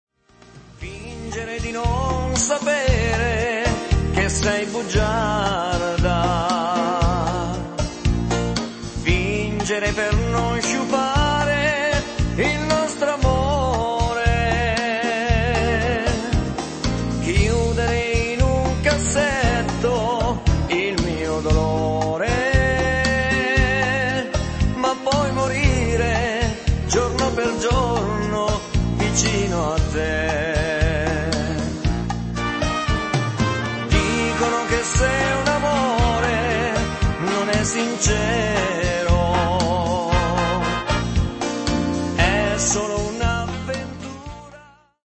bajon